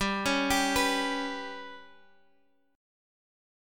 GMb5 chord